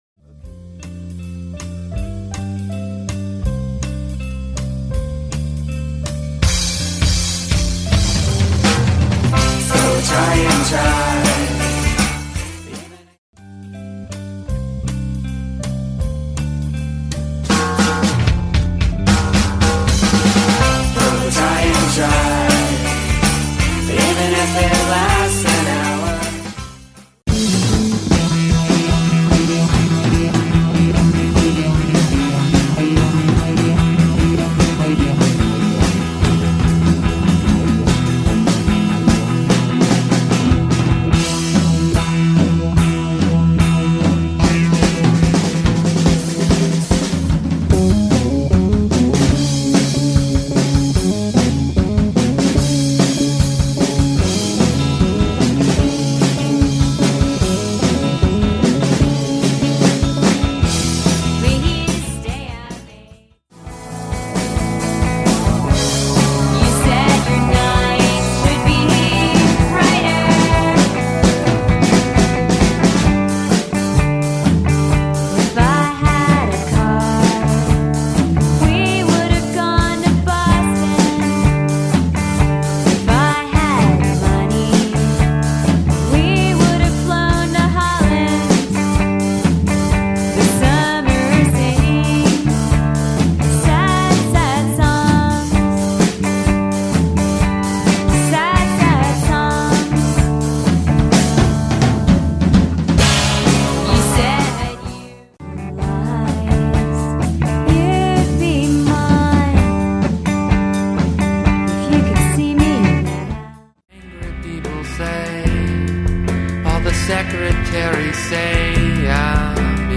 Collection of studio clips.